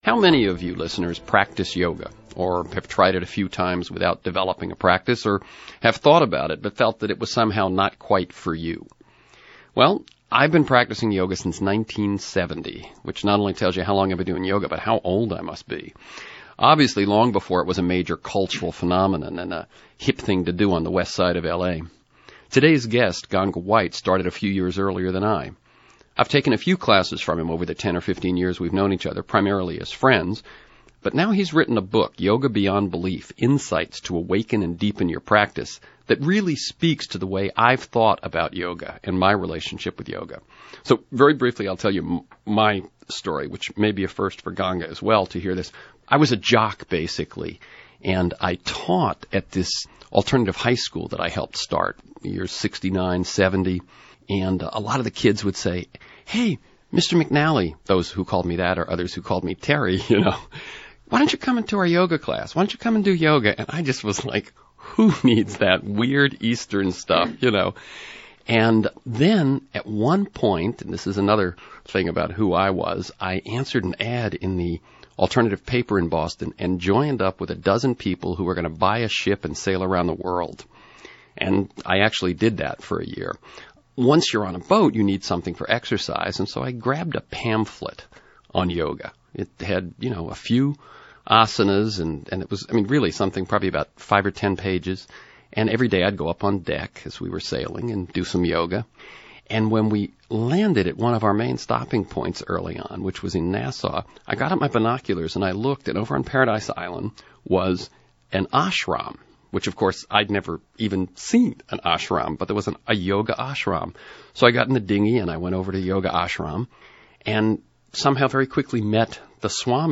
interviewed on KPFK